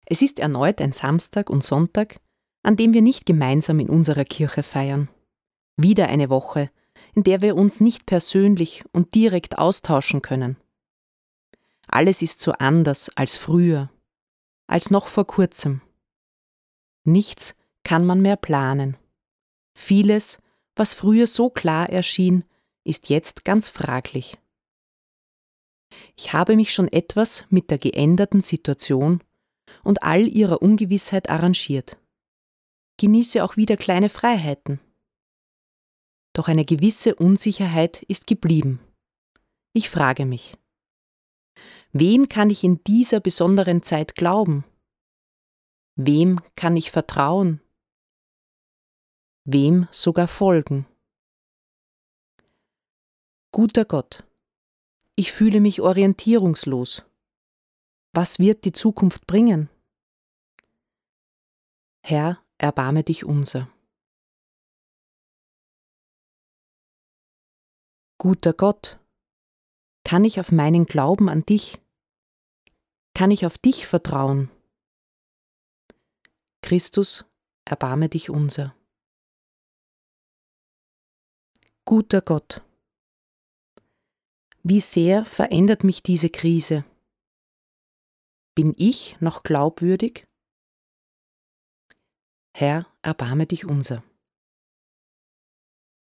02_Eingangstext und Kyrie.mp3